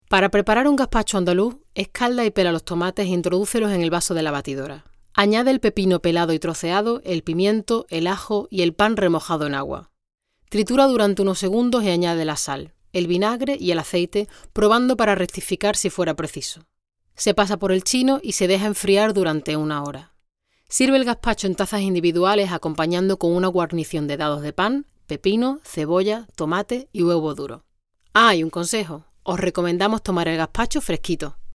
Spanisch. 2014 SPRACHEN Deutsch: Flieβend (C2) Englisch: Flieβend (C1) Italienisch: Gut (A2) Spanisch: Muttersprache (europäisch) Akzente: Castellano, Andalusisch, Mexikanisch, Argentinisch Stimmalter: 30 – 40 Stimme: voll, frisch, dynamisch, warm, facettenreich, weich.
Sprechprobe: Sonstiges (Muttersprache):